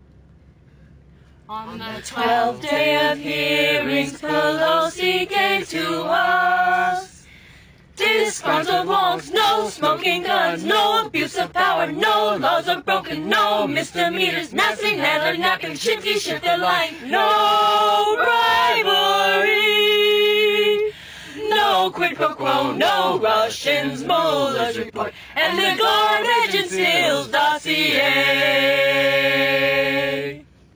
Political Christmas Carols
parody Christmas Carols
AUDIO TAPE: SHORT VERSION (LAST VERSE, 30 seconds)